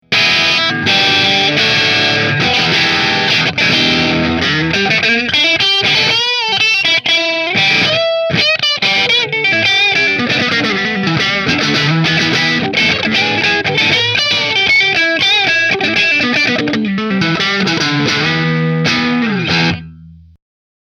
It comes with a mahogany neck through neck, 2 custom wound humbuckers with coil splitting, and a beautiful dark green flame top.
New Orleans Guitars Voodoo Custom Dark Green Middle Through Marshall